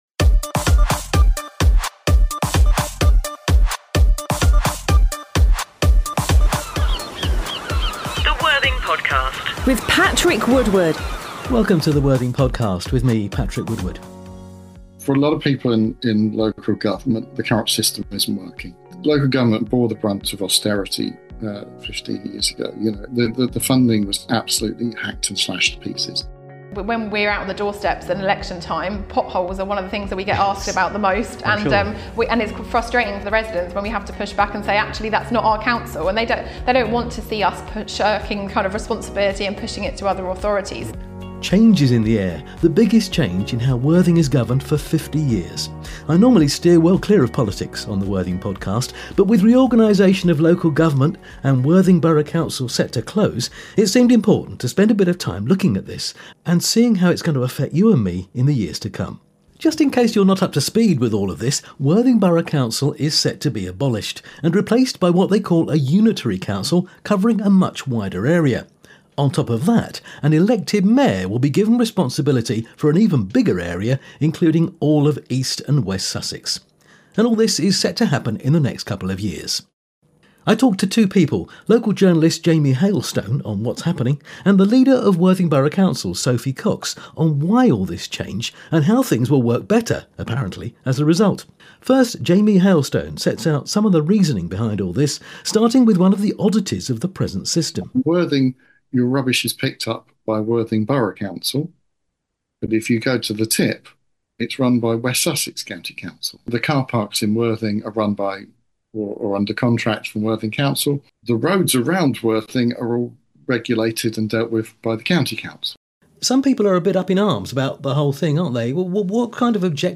with Worthing Council leader Cllr Sophie Cox